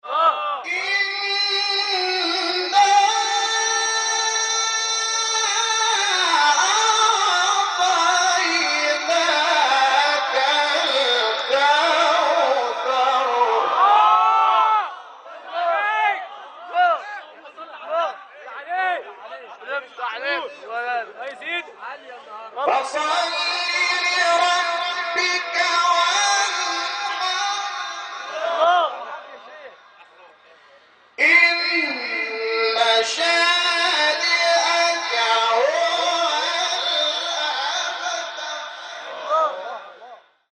تلاوت سوره کوثر استاد محمود شحات | نغمات قرآن | دانلود تلاوت قرآن